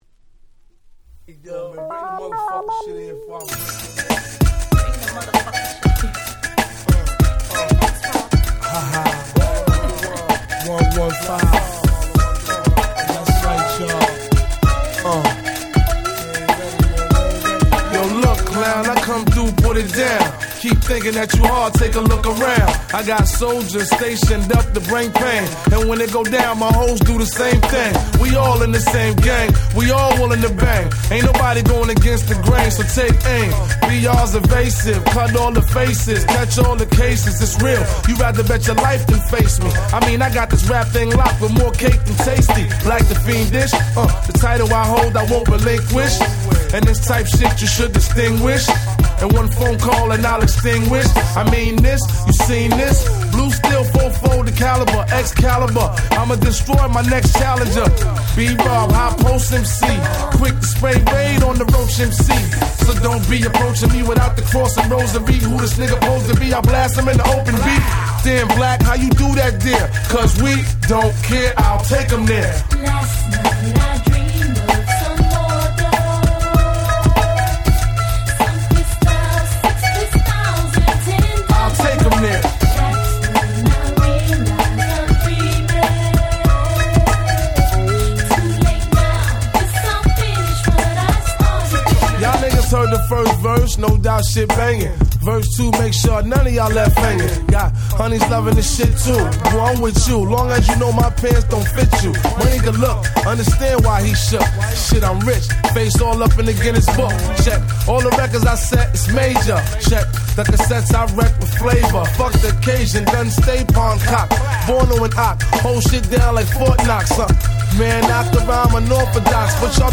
99' Nice Hip Hop !!
オリエンタルなBeatも格好良いです！！